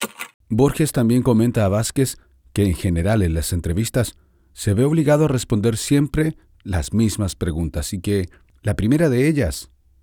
Spanish (Latin-America) voice over
E-learning